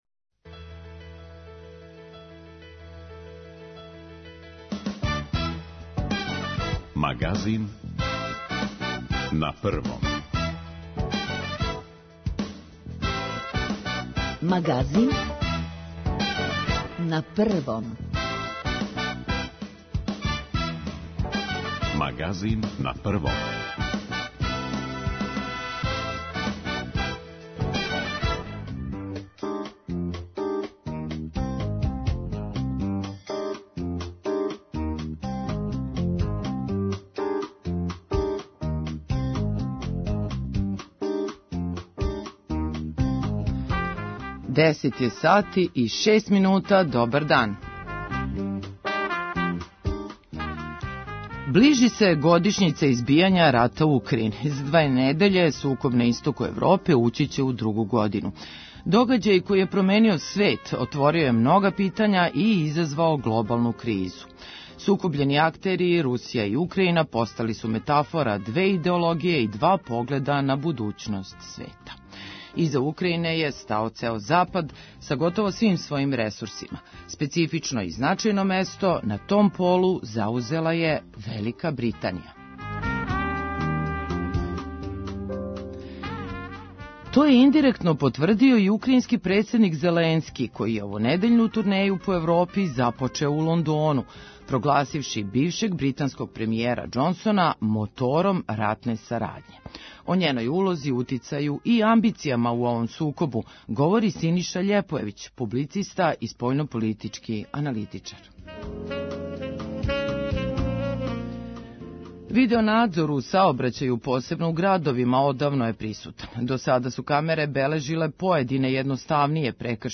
публициста и спољно политички аналитичар